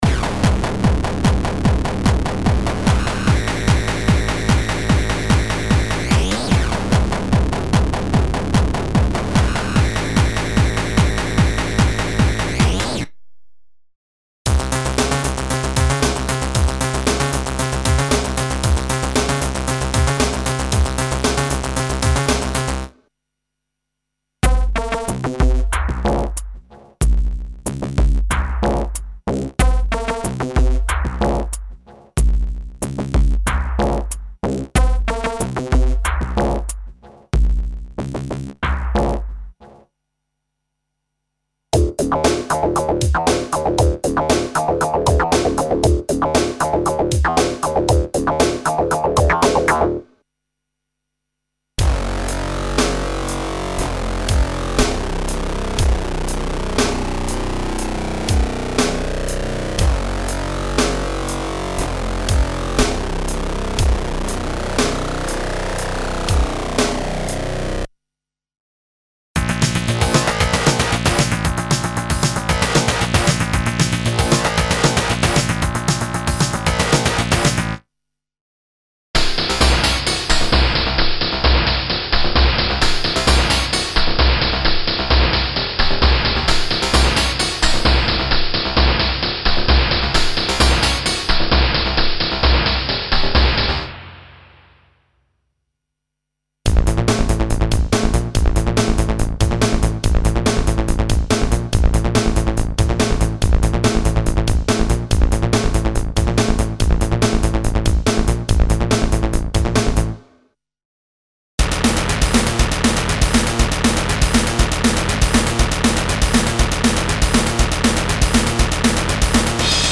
Extreme basses, leads and oscillator waves - aggressive lo-fi sound programs recreating the era of 8-bit samplers and computer sounds, including "paranormal" FM emulations.
Info: All original K:Works sound programs use internal Kurzweil K2500 ROM samples exclusively, there are no external samples used.
K-Works - Extreme Volume 2 - EX (Kurzweil K2xxx).mp3